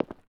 Concret Footstep 01.wav